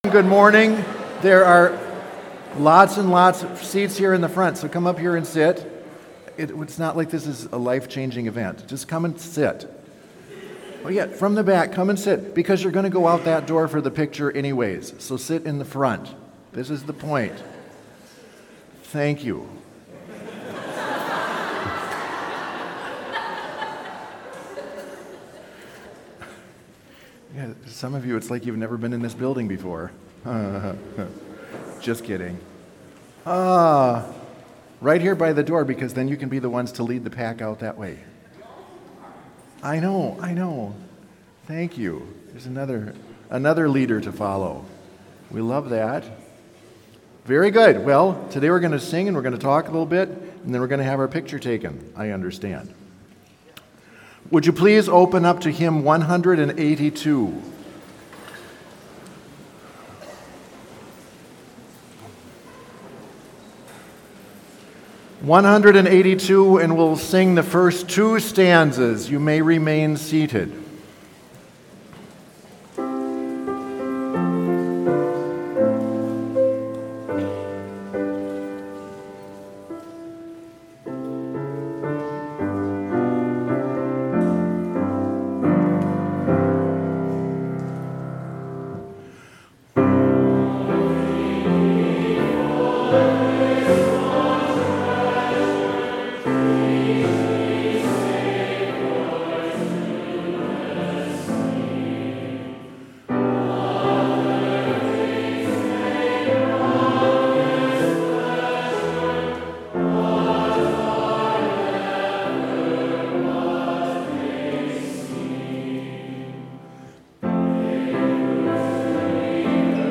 Complete service audio for Chapel - August 24, 2022